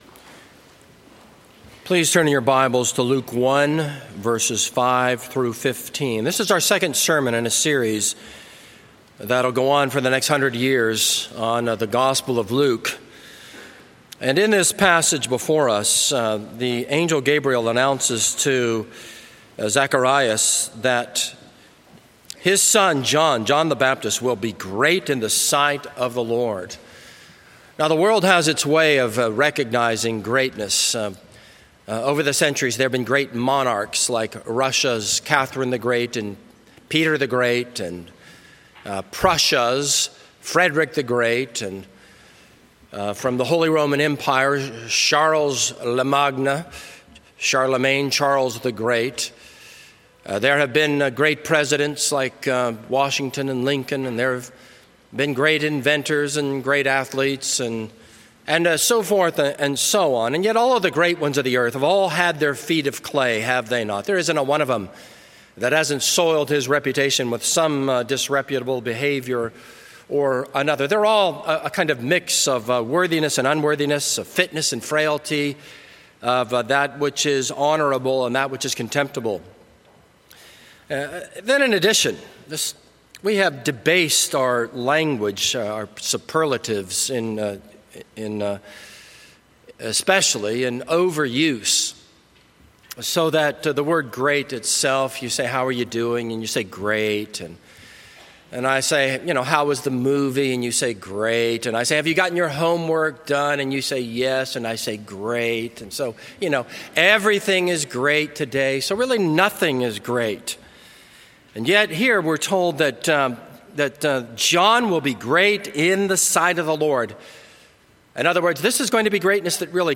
This is a sermon on Luke 1:5-15.